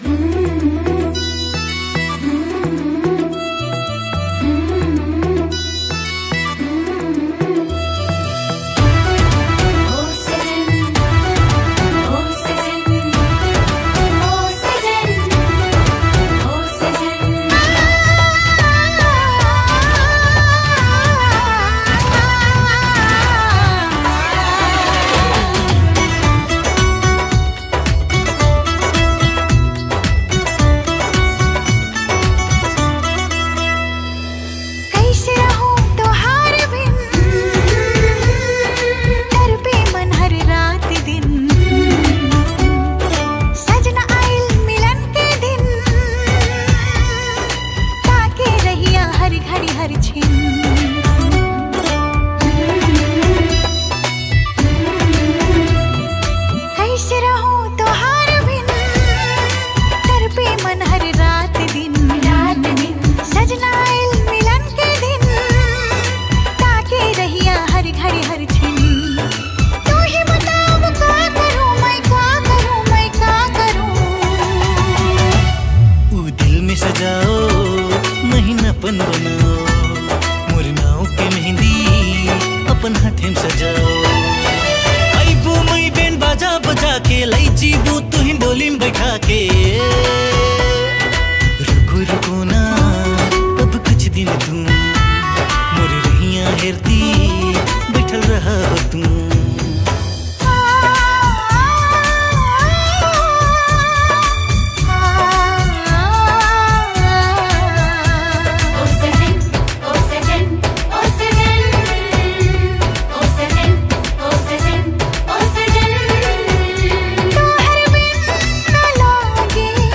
New Tharu Mp3 Song